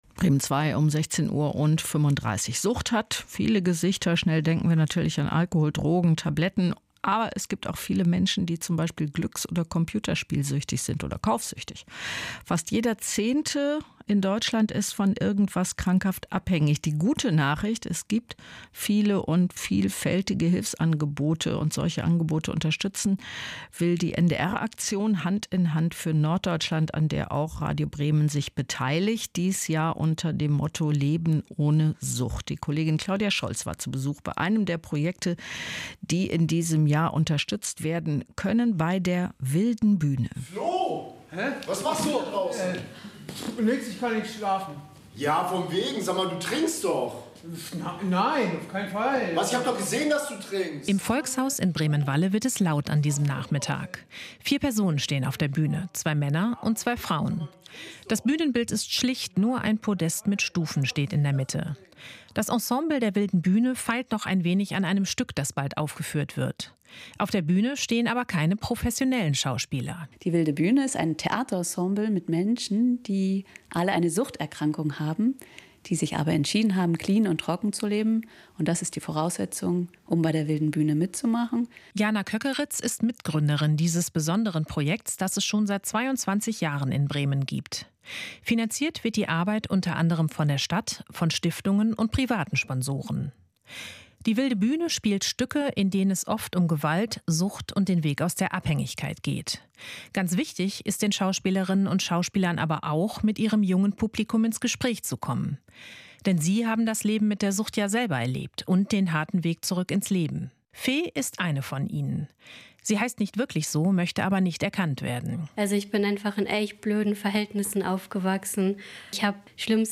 Radiobeitrag "Wilde Bühne e. V." (Bremen Zwei)